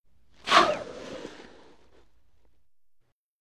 Звук снятия шлема скафандра с выпуском воздуха